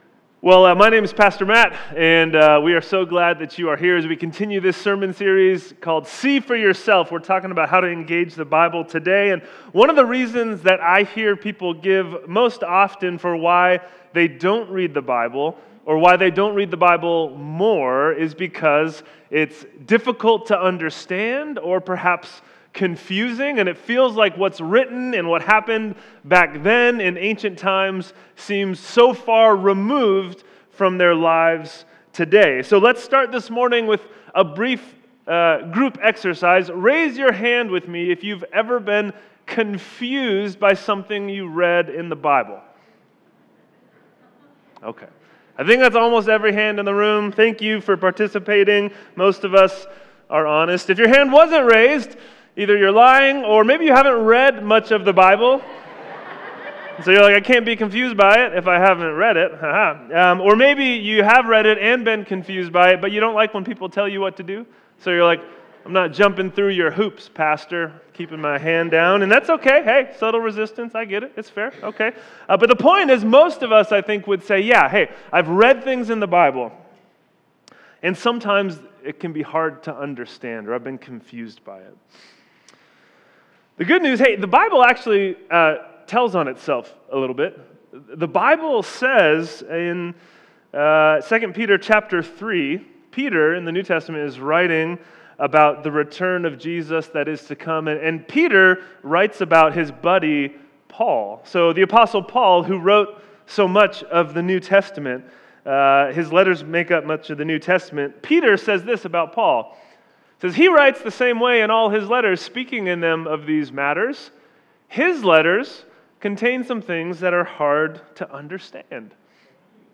Sermons | First Baptist Church of Benicia